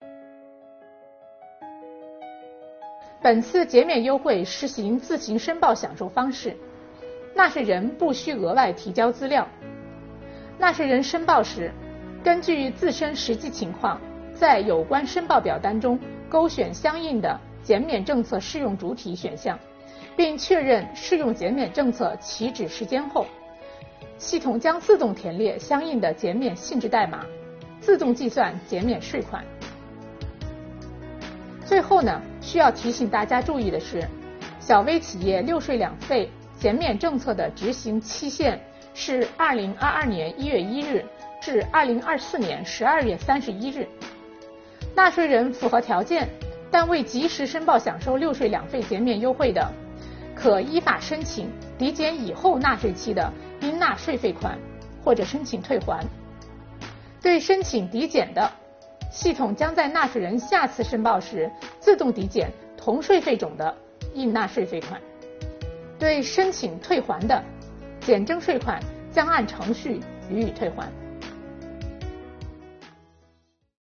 近日，国家税务总局推出新一批“税务讲堂”系列课程，为纳税人缴费人集中解读实施新的组合式税费支持政策。本期课程由国家税务总局财产和行为税司副司长刘宜担任主讲人，解读小微企业“六税两费”减免政策。